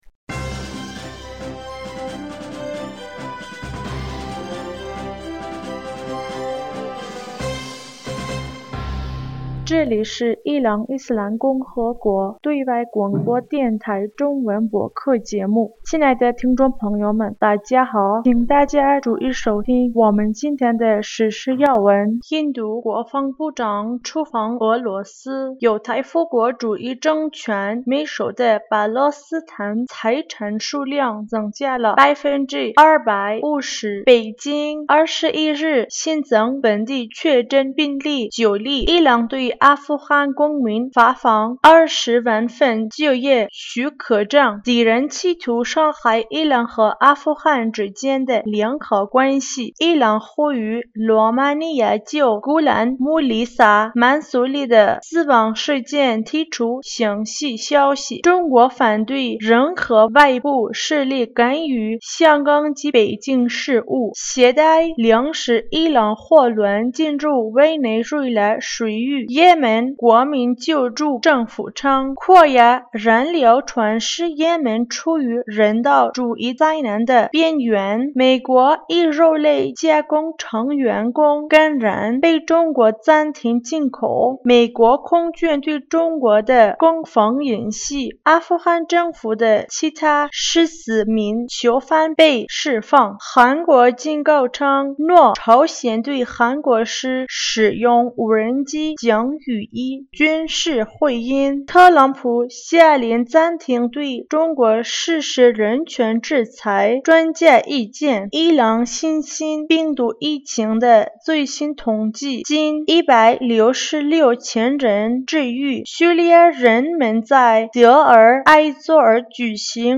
2020年6月22日 新闻